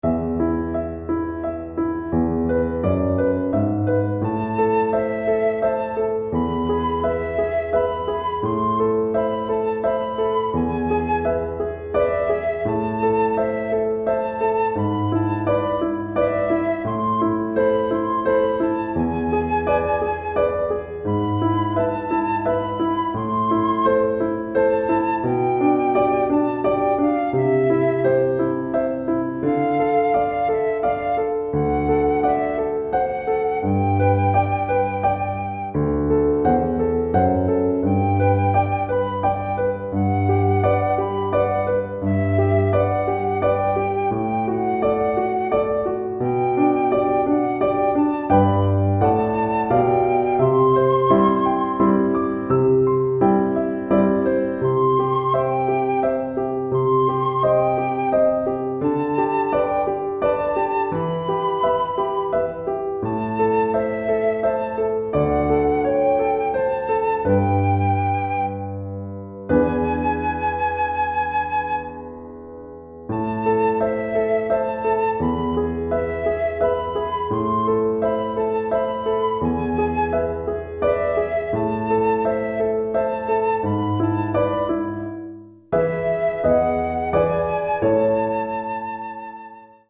Flûte Traversière et Harmonium ou Piano